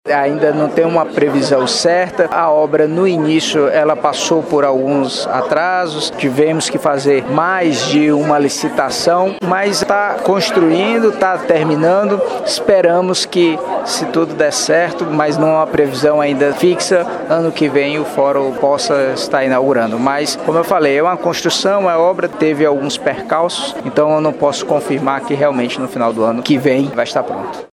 Para saber um pouco sobre o andamento do projeto, o JM conversou com o diretor do Fórum de Pará de Minas, o Juiz Antônio Fortes de Pádua Neto. Ele confirmou que ainda há muito para ser feito no prédio e que a inauguração não deve acontecer por agora.